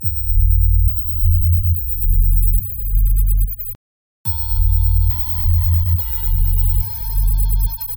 Google Drive (click the download symbol there) WARNING: It has some high level high frequency sounds near the end, so don't play it loud, you may not hear much but it could fry your speakers, (a literal 'killer sample'), and also distress your children and pets.
I selected a few seconds near the end, in the 'add-dist-1' file it is initially undistorted, then the same few seconds with some symmetrical distortion like push-pull tubes plus soft-clipping, then another example, 'add-dist-2', again initially undistorted then repeated with something like single-ended tube distortion.
The first distorted example adds an unpleasant rasping noise, but the second distorted example sounds almost like added bells.